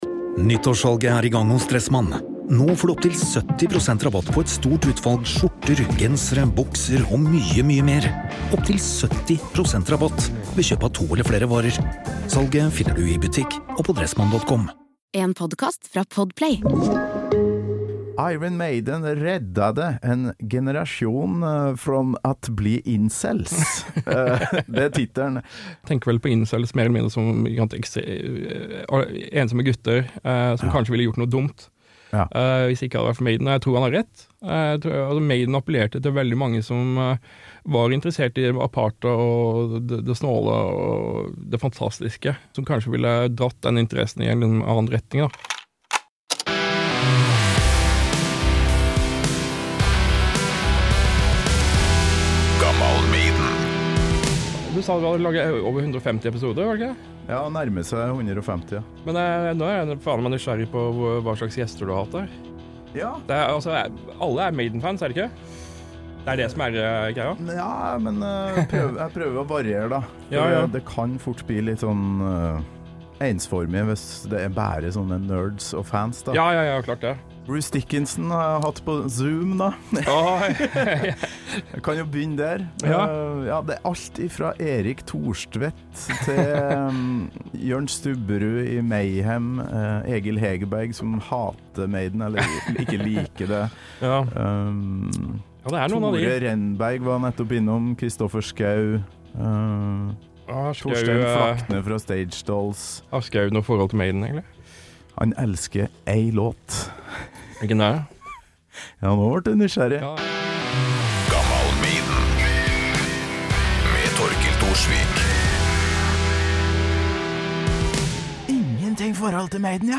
Subkulturer, som for eksempel metal, gir ungdom følelsen av tilhørighet. Hva skjer når kassett-byttingen og fanklubbene forsvinner og det mest ekstreme man finner er lukkede forum på internett? Velkommen til en utrolig interessant, og litt skremmende, samtale - inkludert all den deilige, viktige nørdinga du kan tenke deg om Seventh Son, Moonchild, Derek Riggs og selvfølgelig ... The Evil That Men Do!